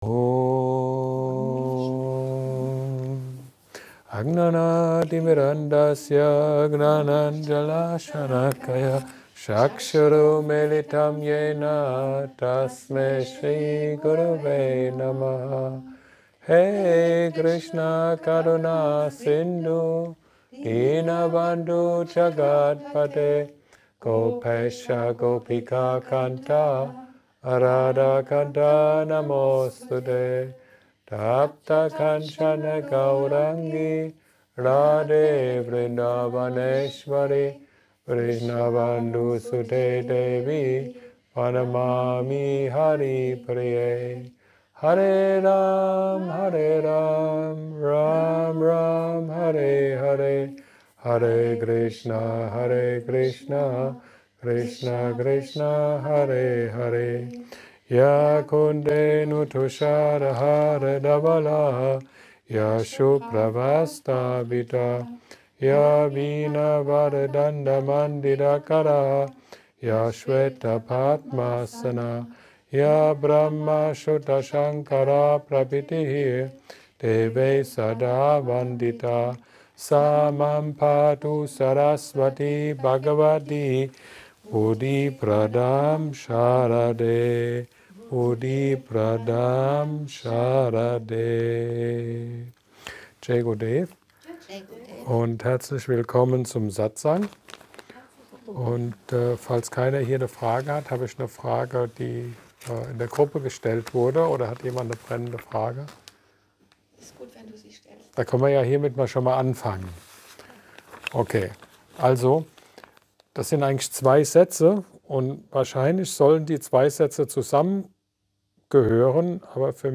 Satsang